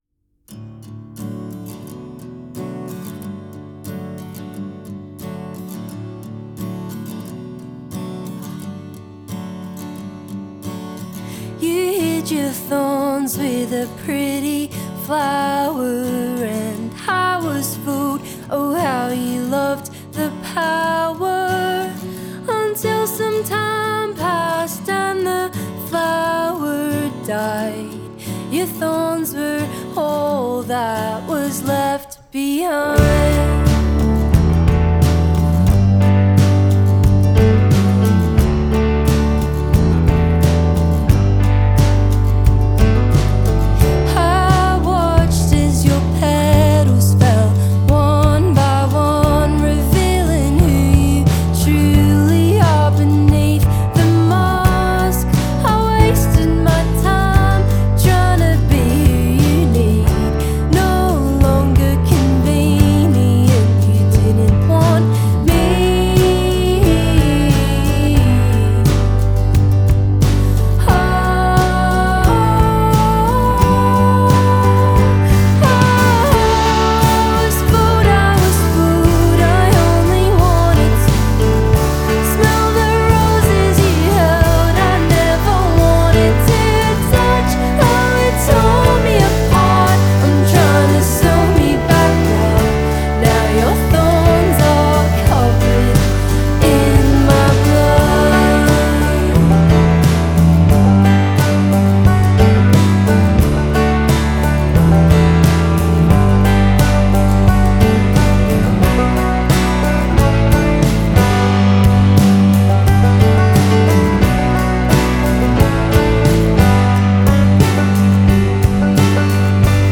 softly strummed acoustic guitar
Acoustic
Folk
Indie
Singer/Songwriter